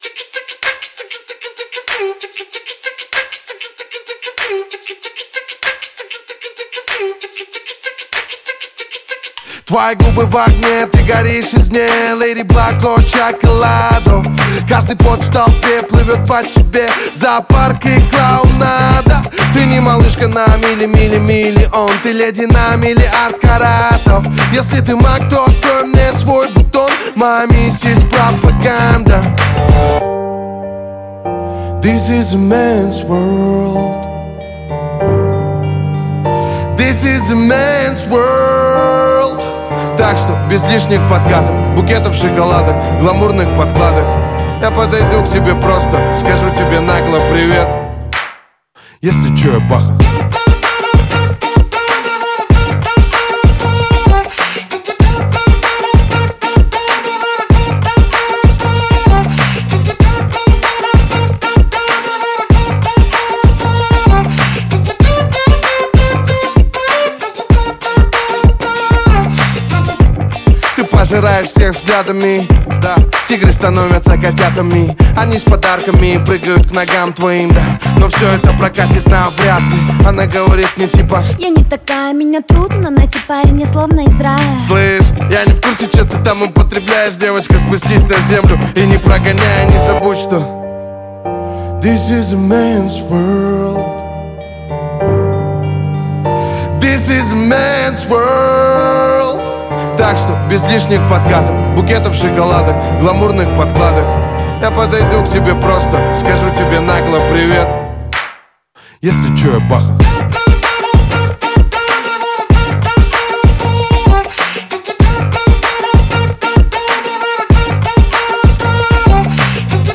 Жанр: Русский рэп / Хип-хоп
• Жанр песни: Русский рэп / Хип-хоп